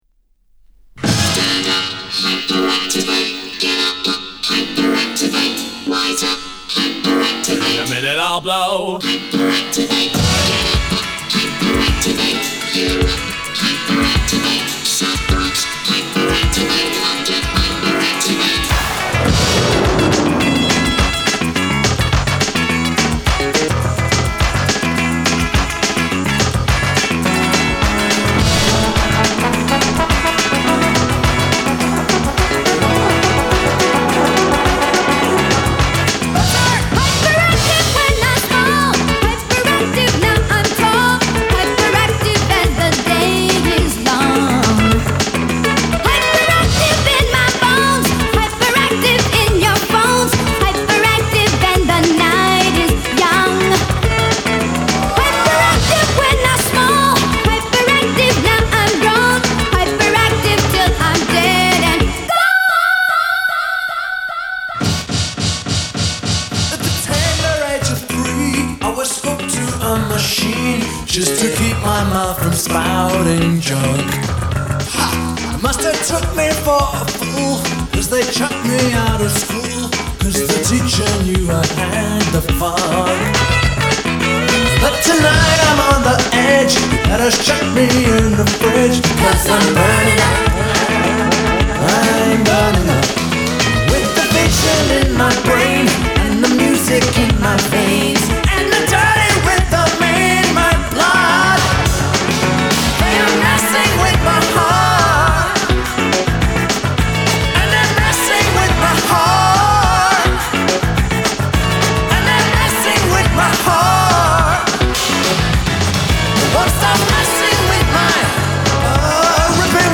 Music / 80s
techno alternative alt_rock rock
You can tap a toe to it.